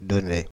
Donnay (French pronunciation: [dɔnɛ]
Fr-Donnay.ogg.mp3